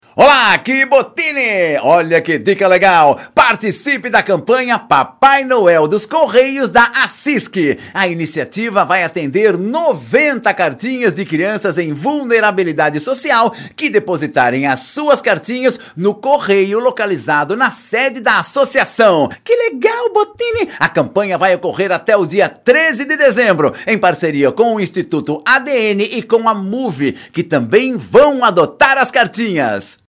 SPOT-Cartinhas-de-Natal-v1.mp3